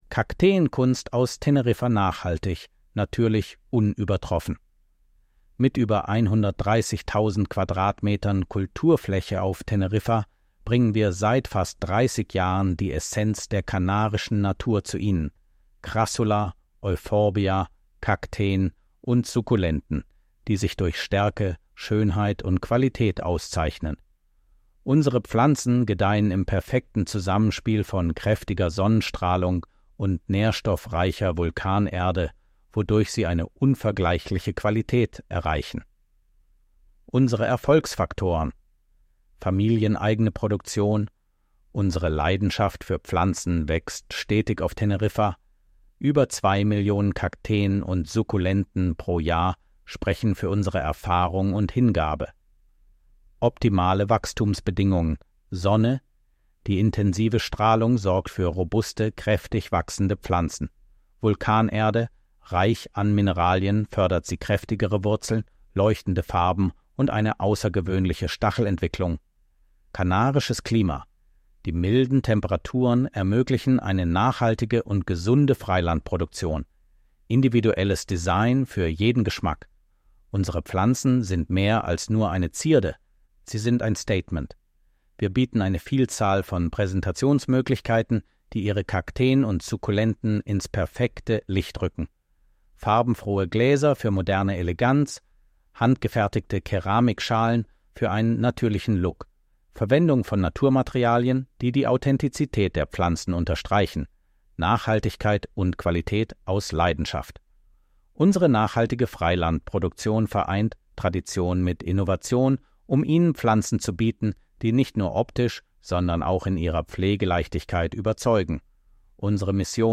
Audio-Datei mit vorgelesenem Text über die Gärtnerei Hohn.